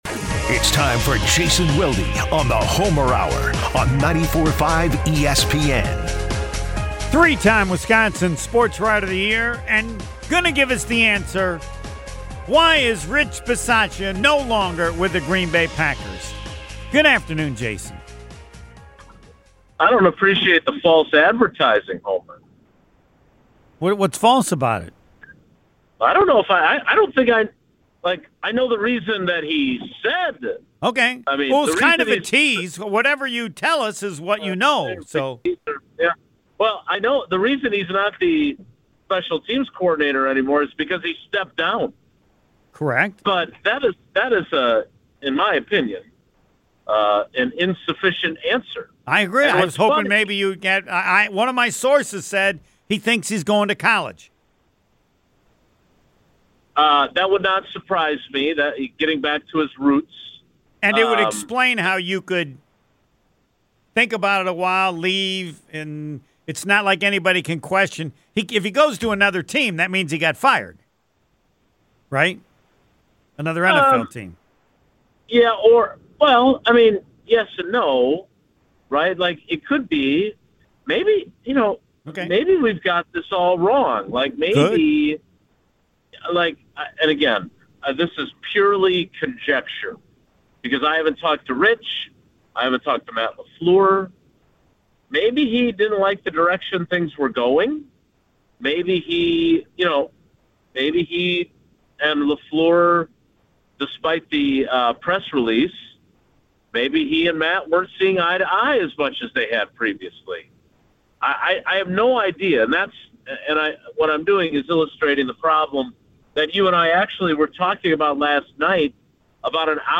The conversation also explored whether Bisaccia played a role in Matthew Golden not being used more as a returner, and how the Packers handled the kicking situation during Brandon McManus’ injury.